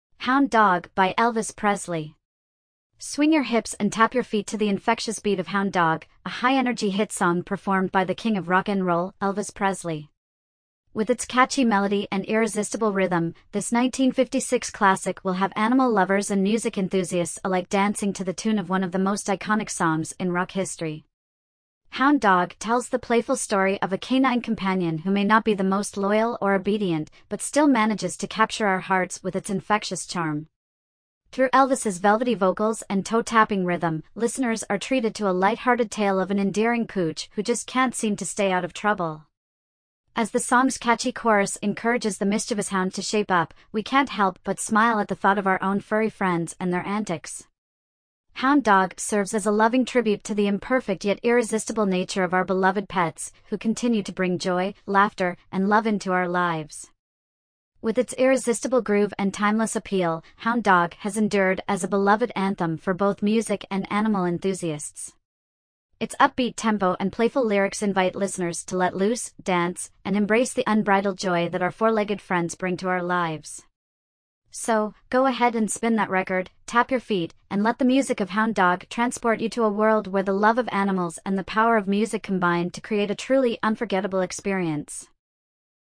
velvety vocals and toe-tapping rhythm